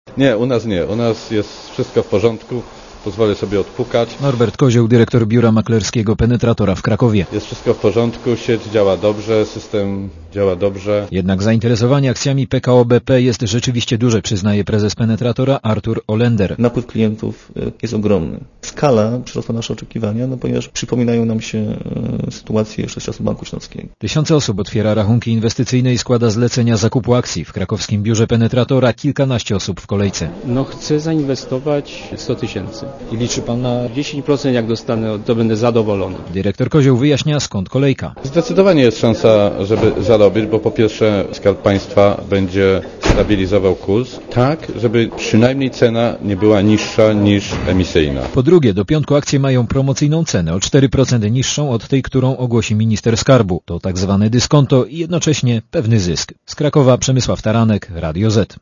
Relacja reportera Radia ZET Zapisy na akcje przyjmowane będą w biurach maklerskich do 3 listopada.